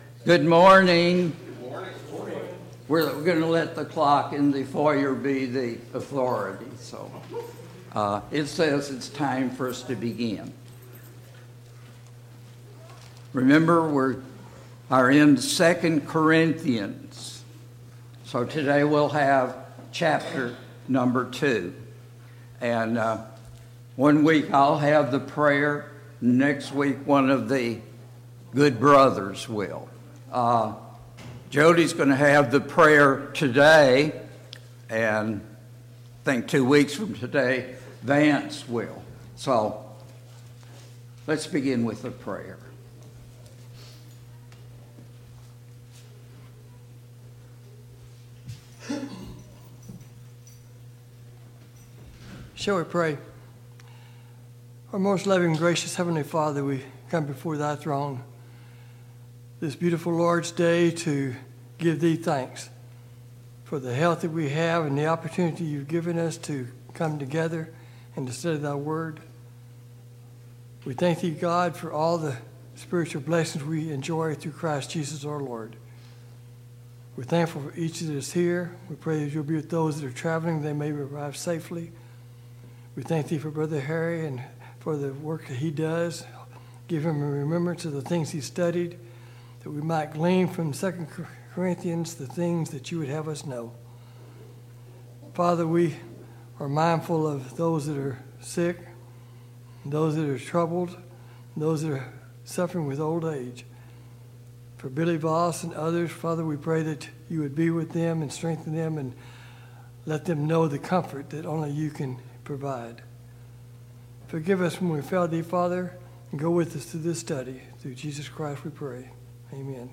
A Study of 2 Corinthians Passage: 2 Corinthians 2 Service Type: Sunday Morning Bible Class « 11.